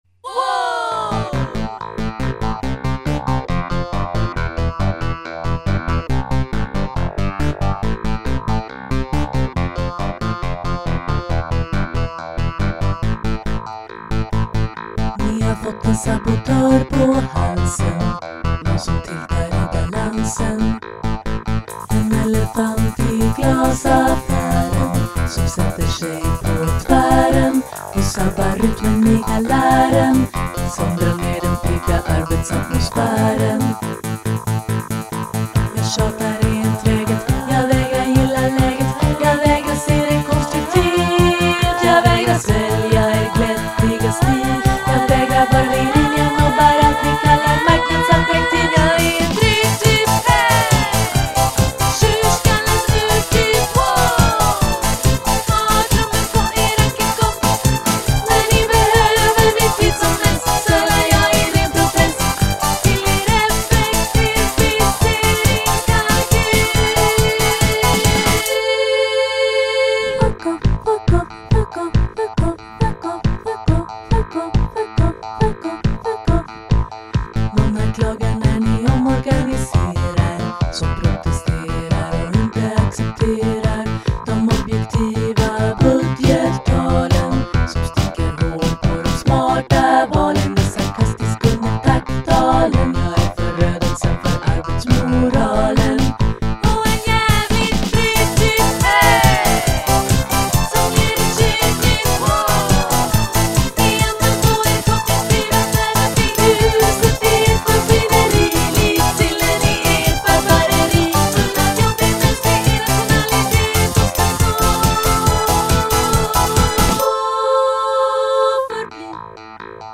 piano, dragspel, sång
Saxofon
Fiol
Trumprogrammering & synth
Cello